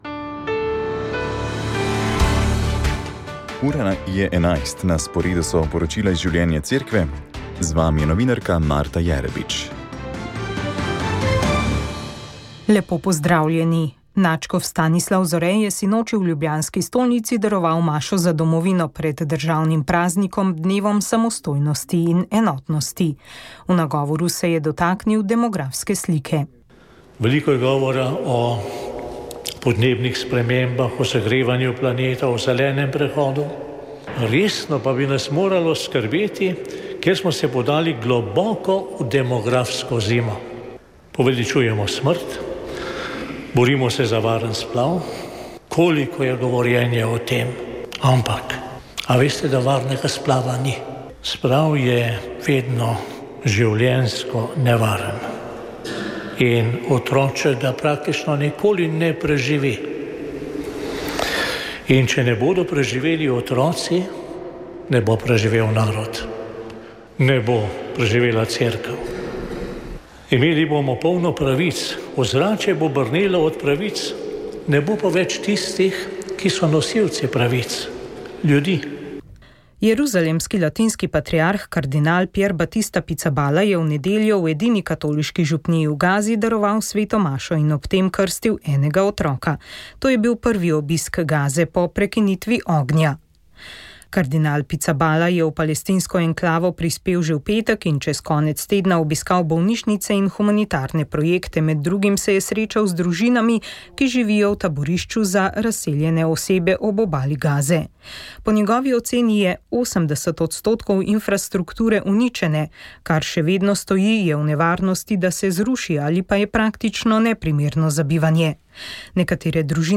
Informativne oddaje